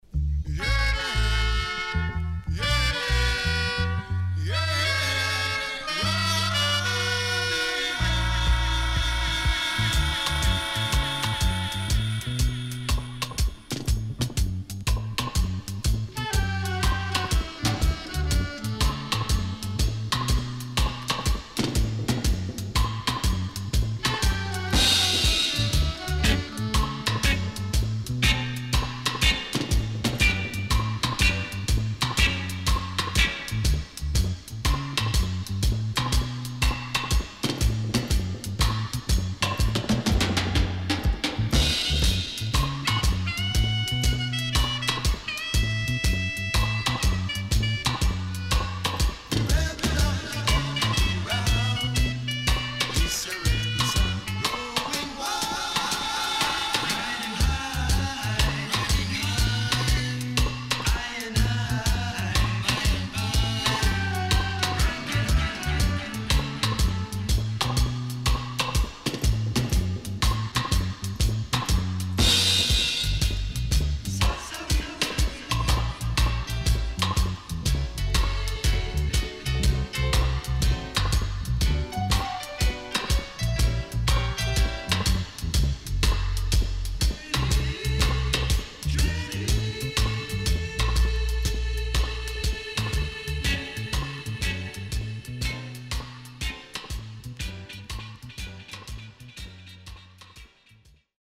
CONDITION SIDE A:VG+
SIDE A:少しチリノイズ入ります。